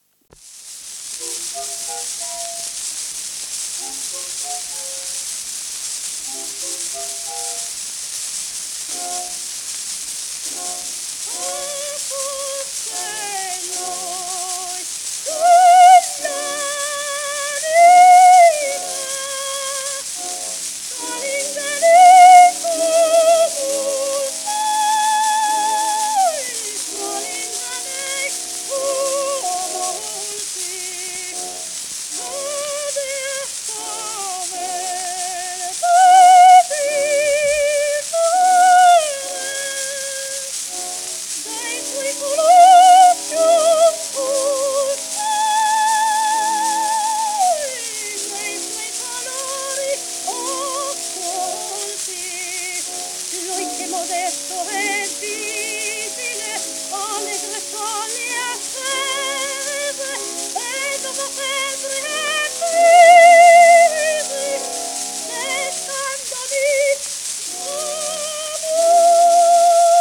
w/オーケストラ
12インチ片面盤
1911年録音
旧 旧吹込みの略、電気録音以前の機械式録音盤（ラッパ吹込み）